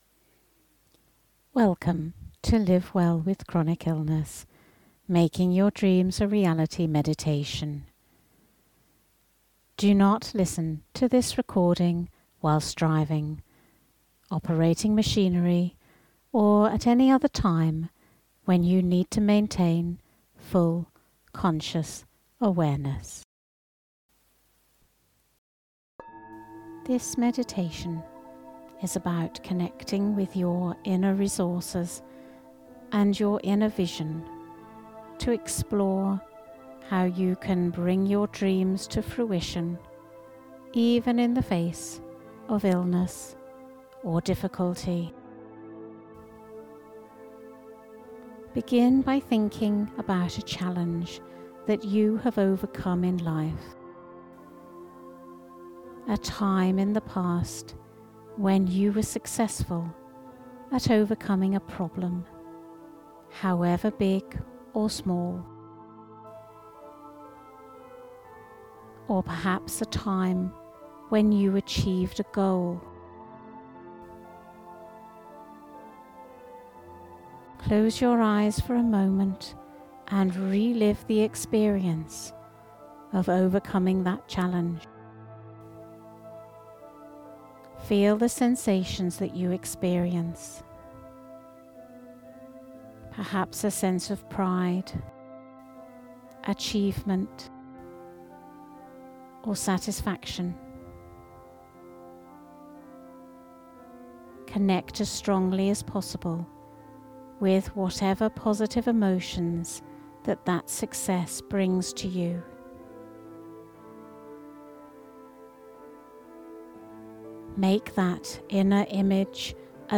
Making your Dreams a Reality Meditation.mp3